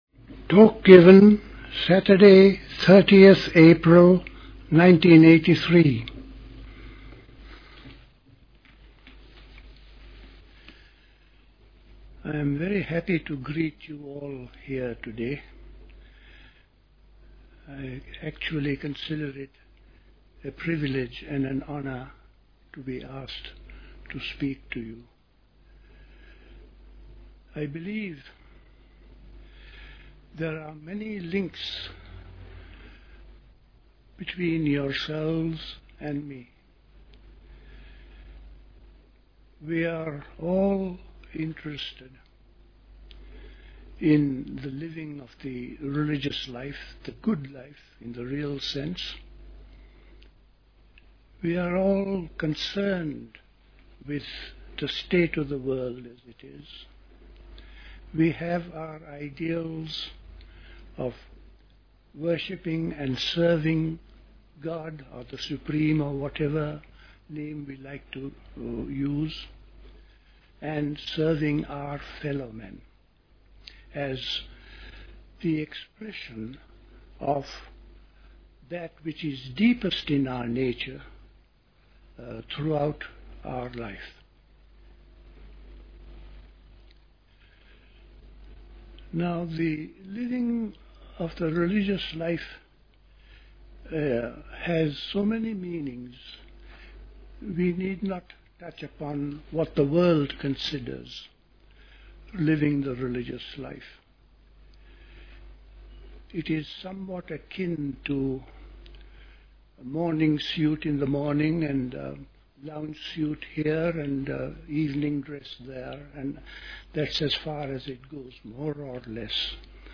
A talk